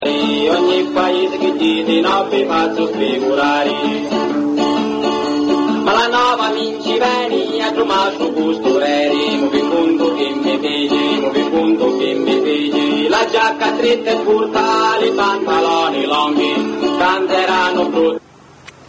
I CANTI
In Calabria riescono a registrare circa 130 brani.